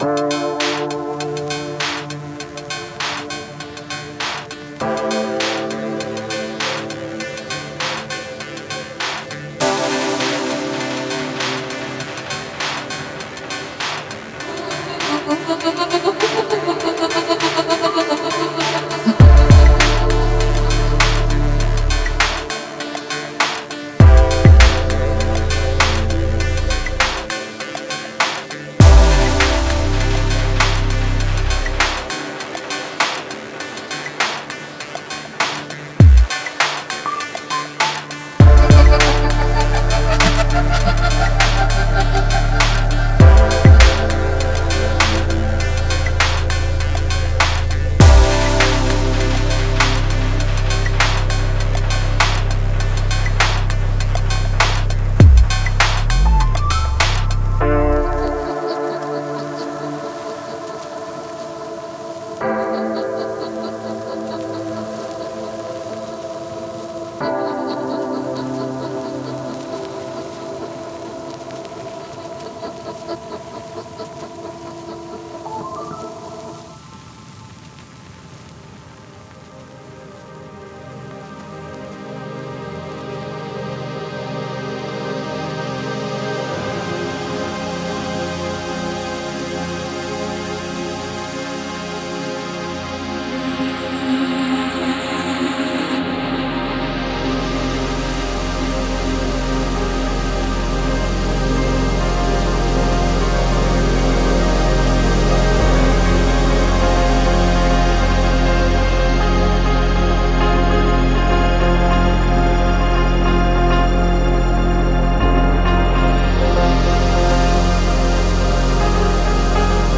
Genre:Ambient
かすれた記憶と影に包まれた空間へと旅し、テクスチャが主導し、余韻が長く残る世界を体感できます。
59 Atmosphere Loops
20 Pad Loops
20 Bass Loops
10 Drum Loops